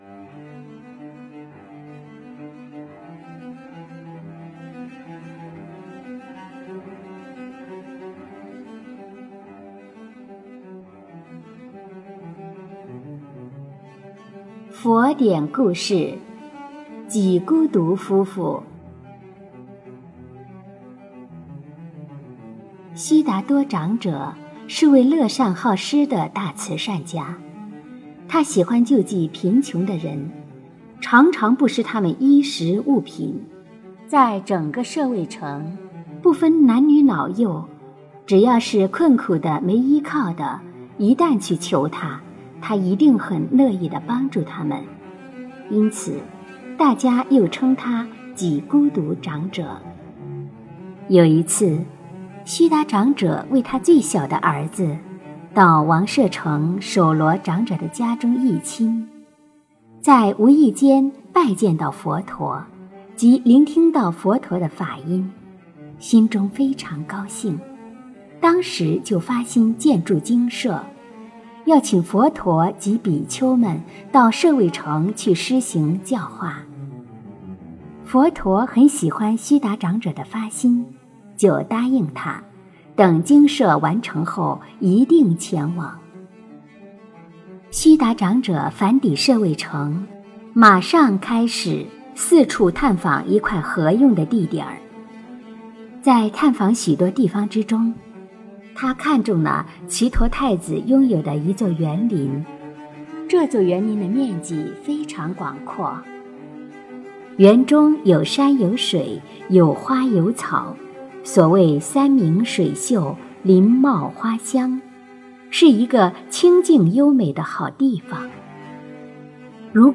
诵经
佛音 诵经 佛教音乐 返回列表 上一篇： 狗转王女 下一篇： 狱中生白莲 相关文章 楞严经02--梦参法师 楞严经02--梦参法师...